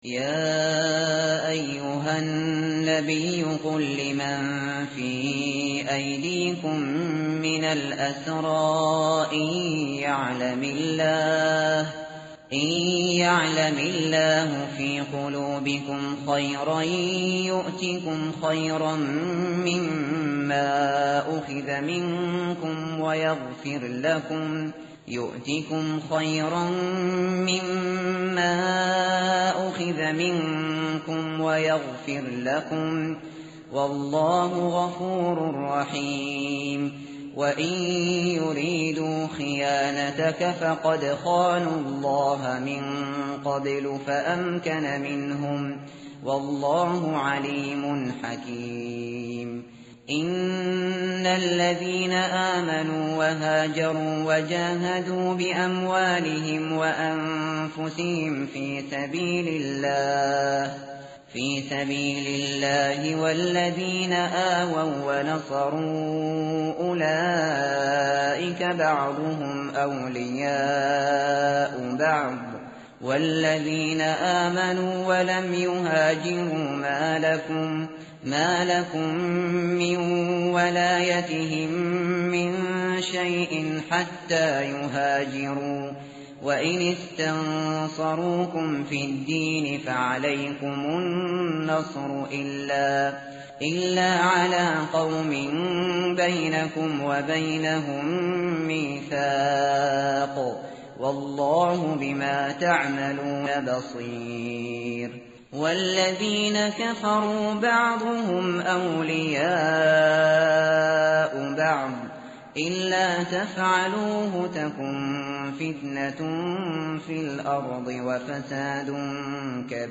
tartil_shateri_page_186.mp3